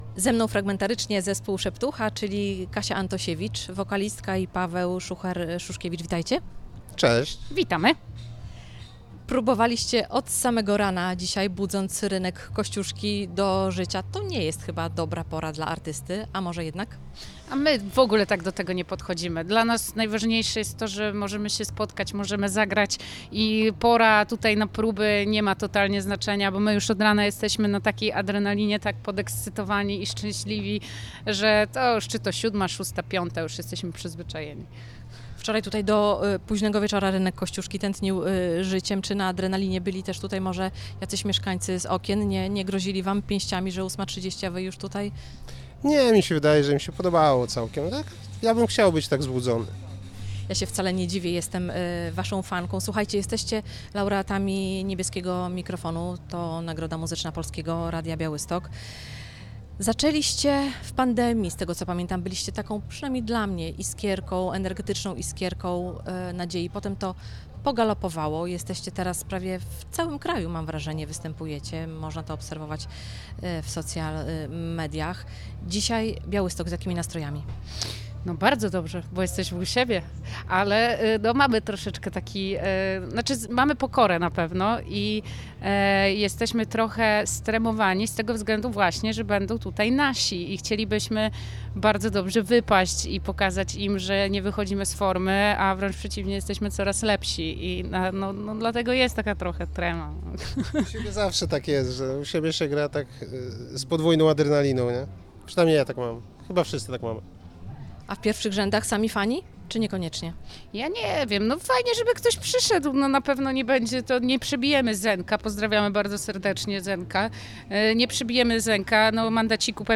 Jest też nasze plenerowe studio - nadajemy z Rynku Kościuszki od 12:00 do 17:00.